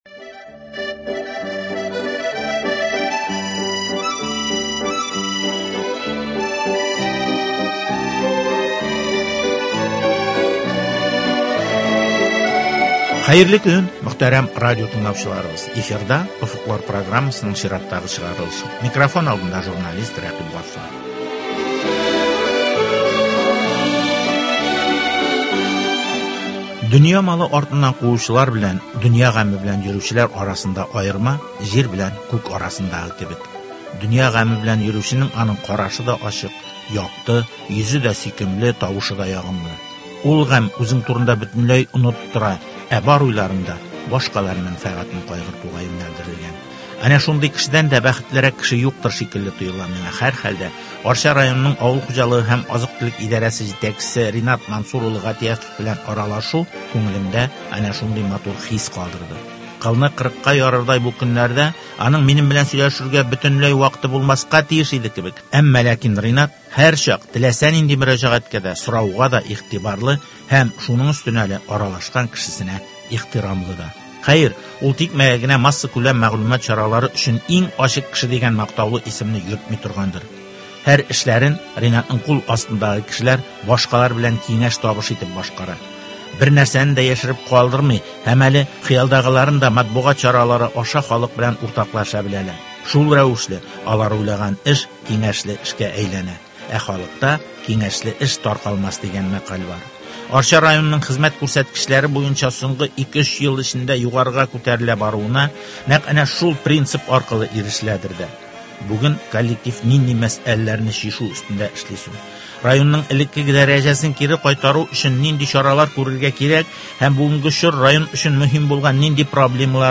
Арча районының авыл хуҗалыгы һәм азык-төлек идарәсе җитәкчесе Ринат Гатиятов белән кышкы мәшәкатьләр, район алдында торган проблемалар, аларны чишү юлларын тәгаенләү темасына багышланган әңгәмә.